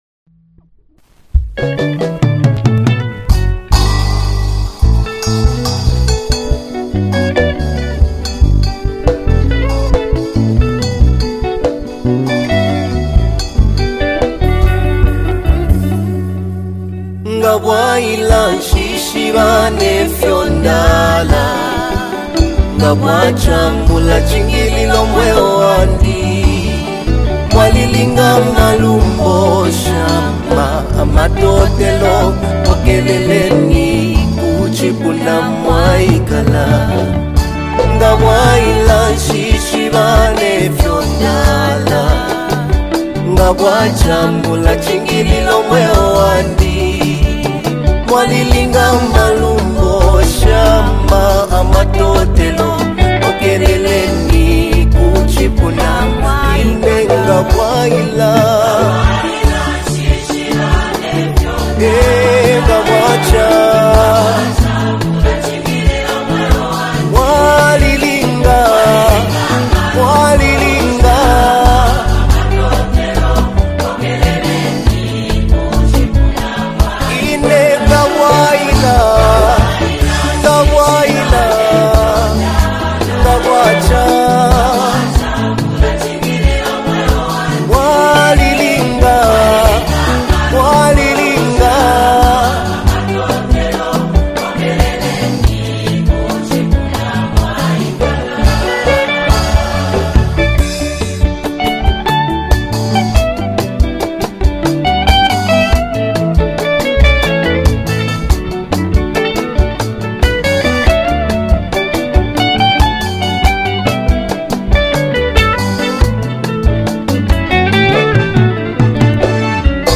SPIRIT-FILLED WORSHIP ANTHEM | 2025 ZAMBIA GOSPEL